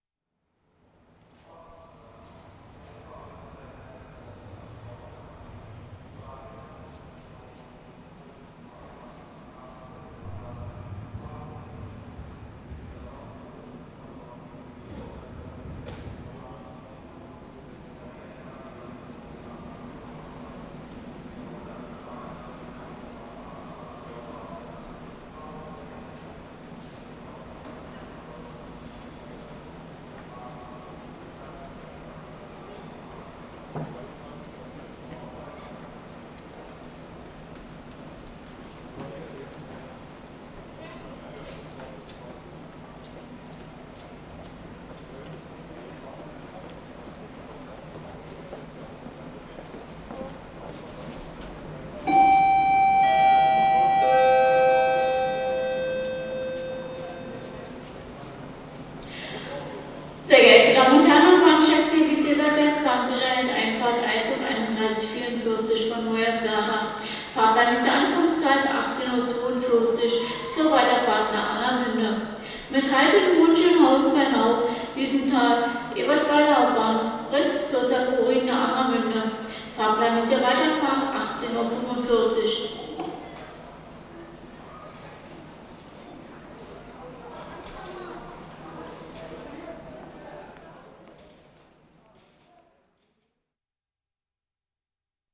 Lichtenberg Railway Station, passage (gong and announcement at 0:52) 1:35